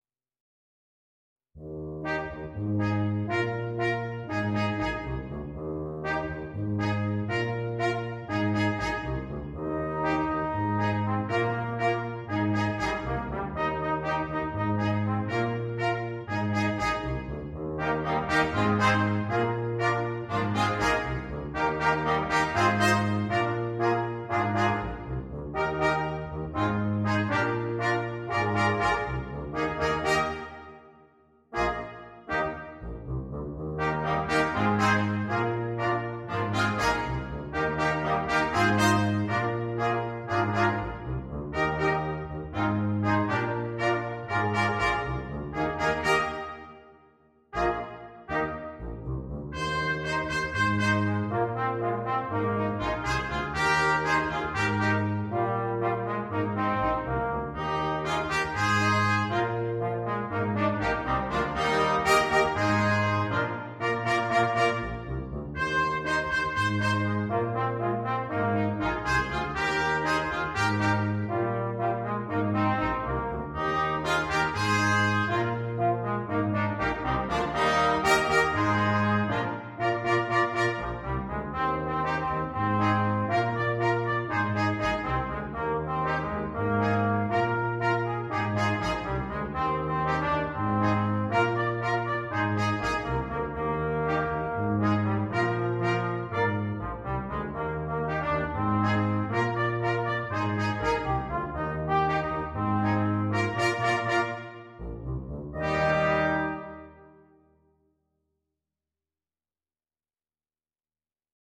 для брасс-квинтета.
• автор музыки: кубинская патриотическая песеня.